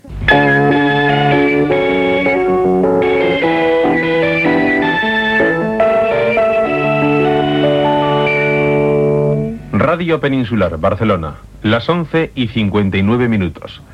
eba93854aa1ed18f22d0552e6de4191aaff462ed.mp3 Títol Radio Peninsular de Barcelona Emissora Radio Peninsular de Barcelona Cadena RNE Titularitat Pública estatal Descripció Sintonia de l'emissora, identificació i hora.